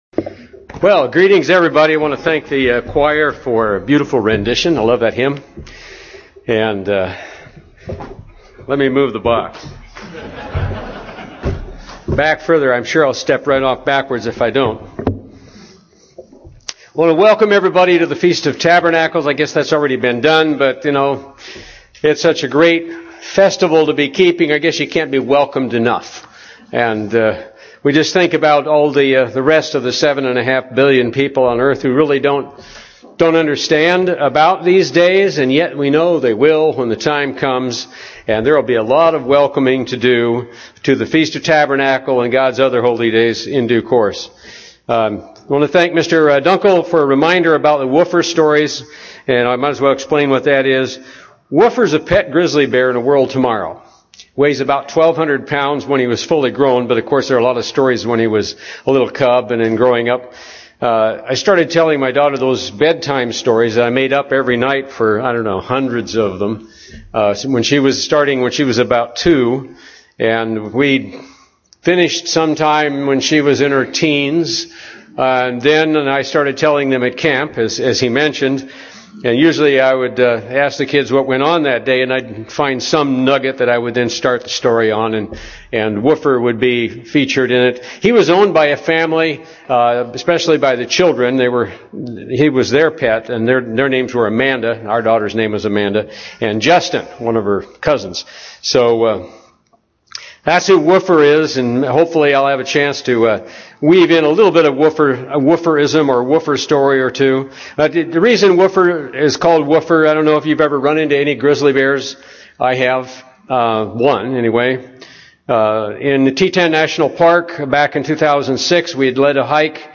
This sermon was given at the Cincinnati, Ohio 2013 Feast site.